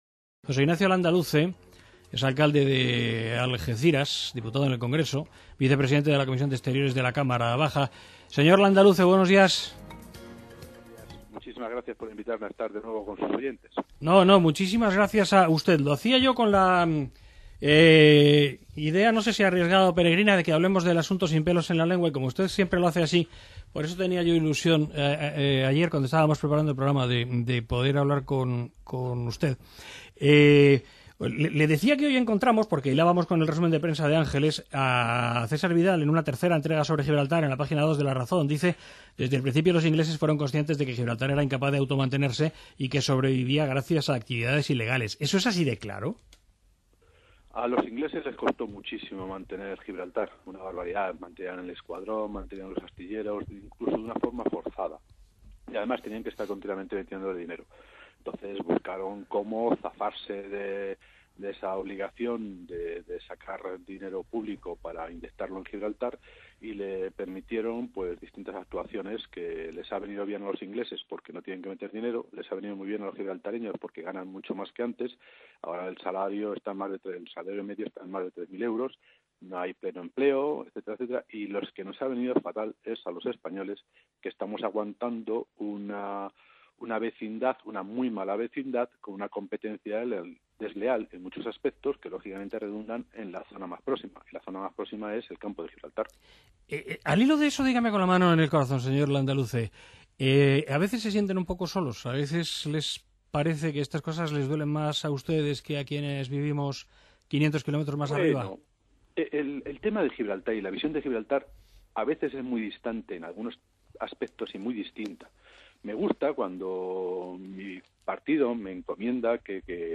Entrevista a José Ignacio Landaluce, alcalde de Algeciras